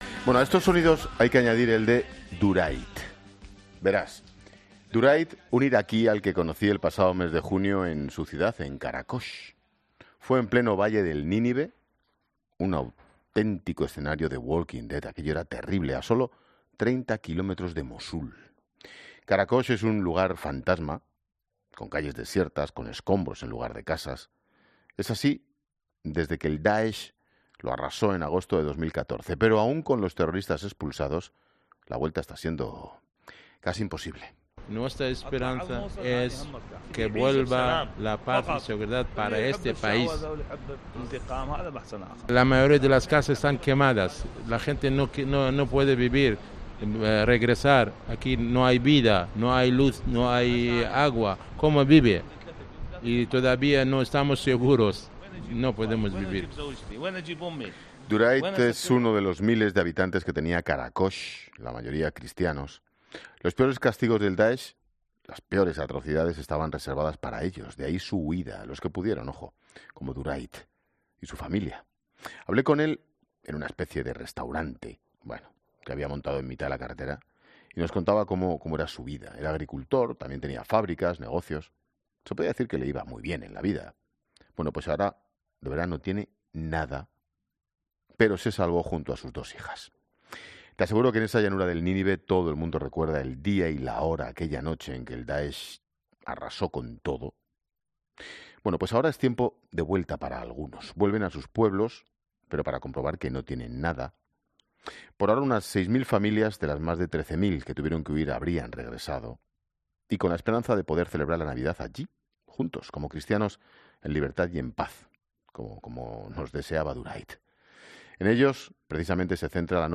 El Obispo de San Sebastián ha estado en La Tarde de COPE